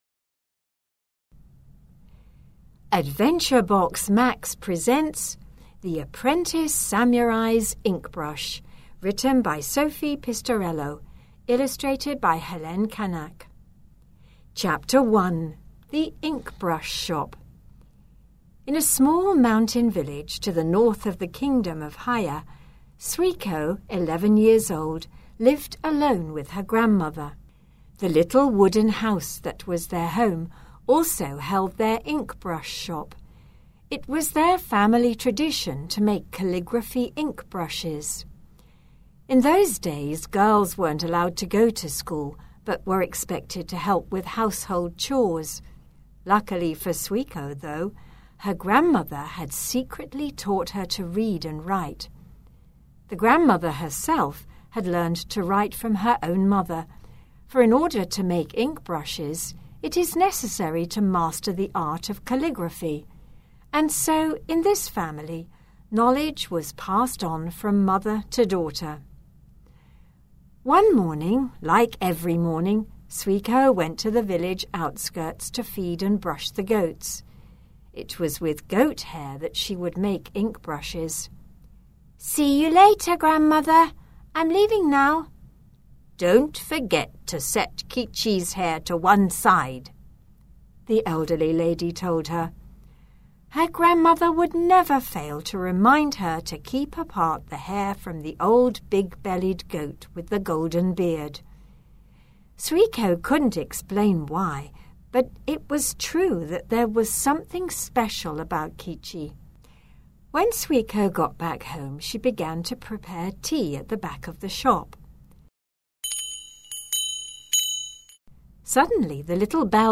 The recording by professional actors brings the stories to life while helping with the comprehension and the pronunciation.